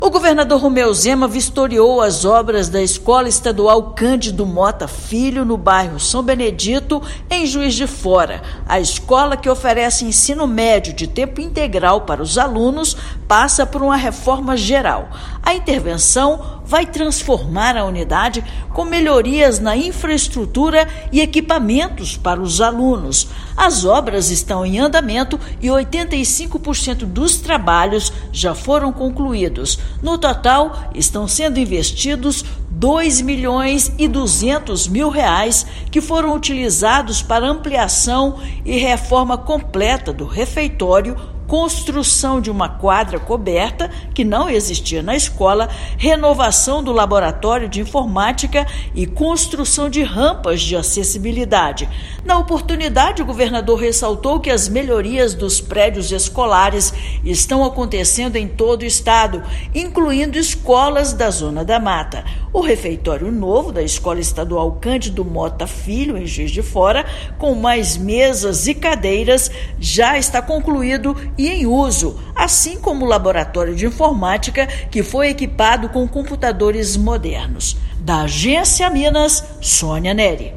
Escola Estadual Professor Cândido Motta Filho fica no bairro São Benedito e recebeu mais de R$ 2 milhões em investimentos. Ouça matéria de rádio.